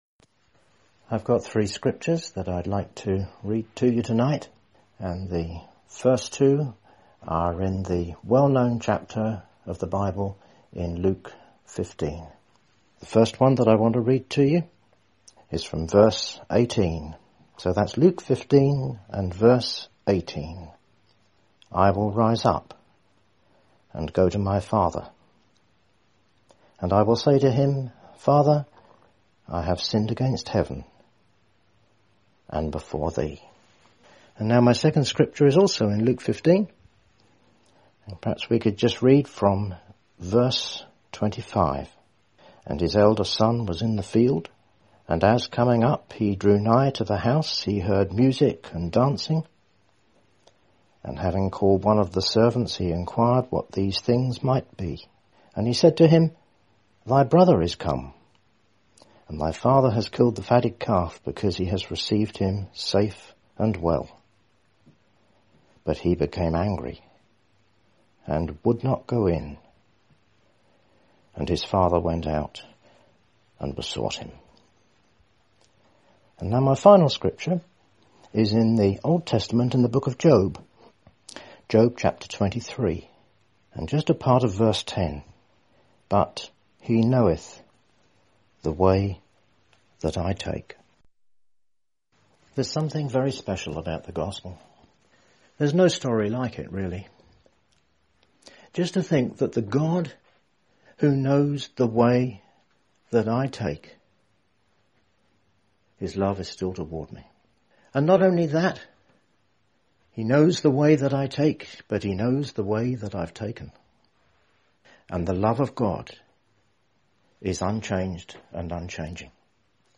In this Gospel preaching, you will hear of three ways that lead to blessing.